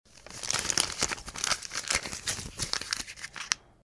Звуки бумажных денег
Шуршание денежных банкнот